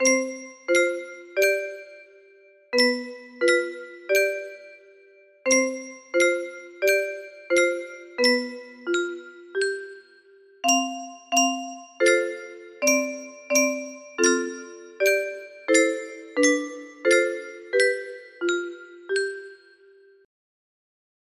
Dreaming of Stars music box melody
Full range 60